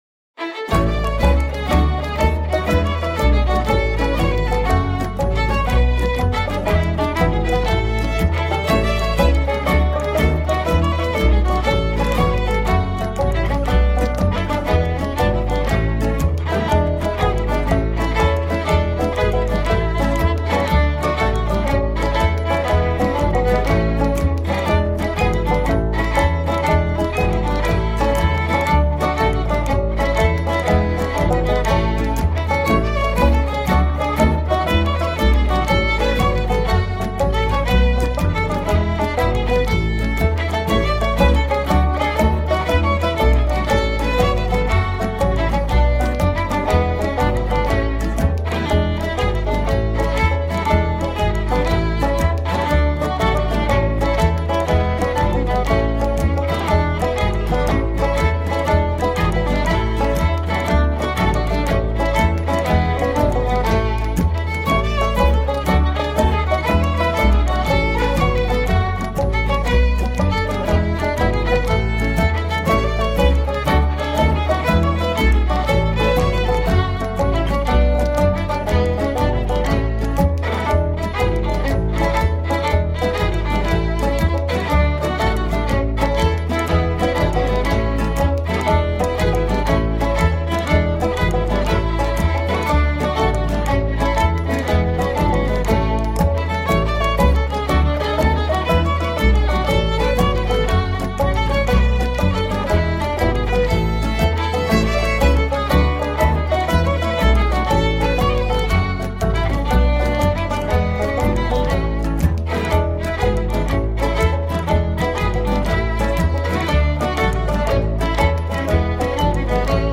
Accordéon / Accordion
Guitar - Banjo - Spoons - Foot tapping
Bodhran - Washboard - Bones